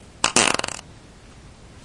描述：放屁
标签： 爆炸 flatulation 肠胃气胀 气体 噪声 poot 怪异
声道立体声